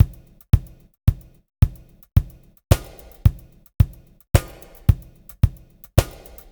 WVD DRUMS -R.wav